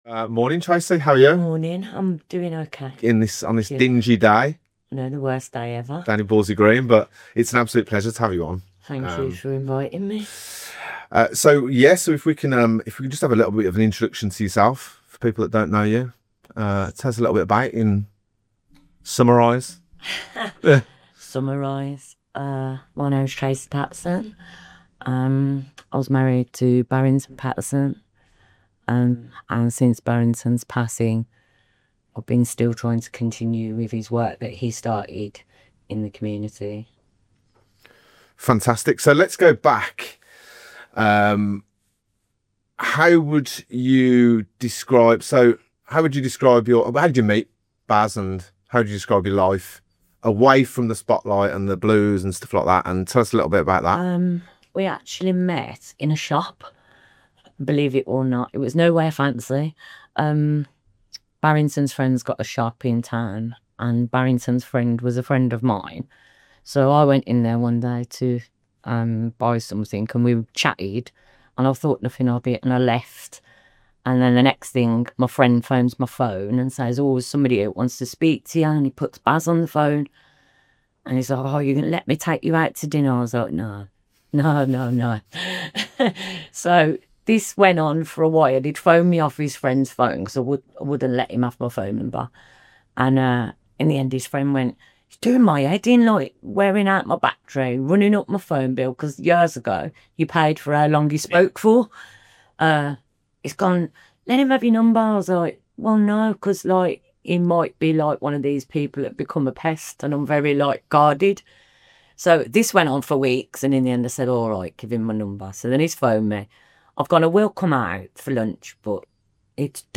joins us for a powerful, emotional, and brutally honest conversation about life before and after her husband’s passing.